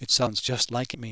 CHATR speech database example
Example 2: focus on `like':